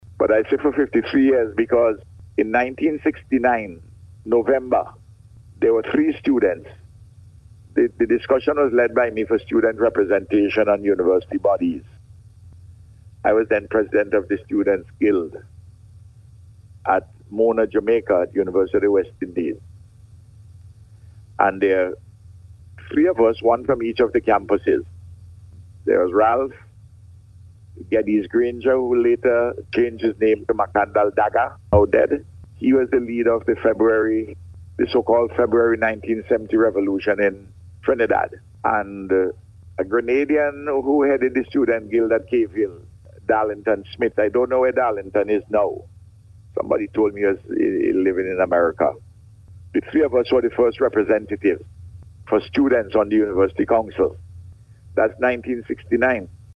Speaking on Radio this morning, Prime Minster Gonsalves outlined some of the decisions taken at yesterday’s Council Meeting.